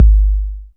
RAP WAVE.wav